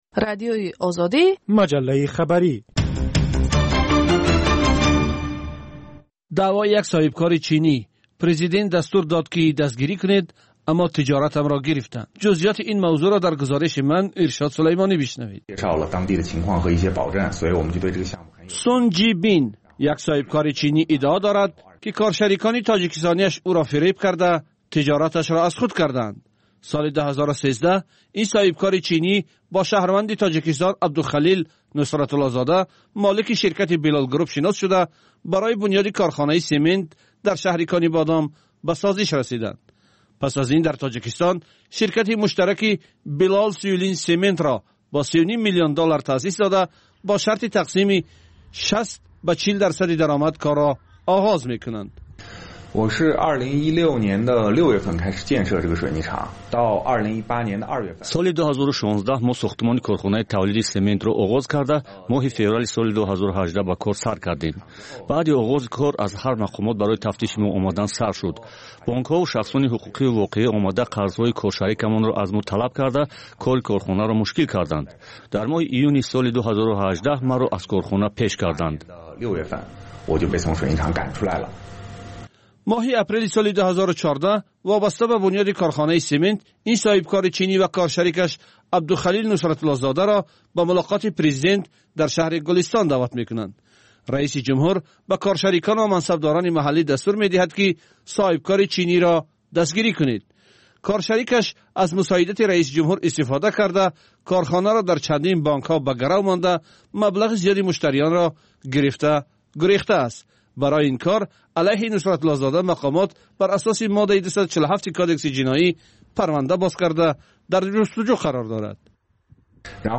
Пахши зинда